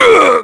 Ricardo-Vox_Damage_kr_01.wav